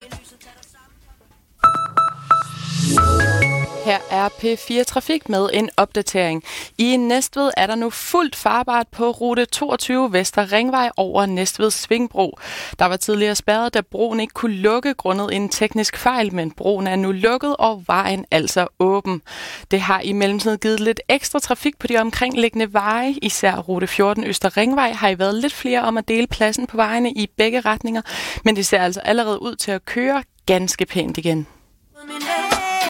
Radiomeldinger, P4
Trafikmelding, P4 Sjælland, den 25. februar 2023: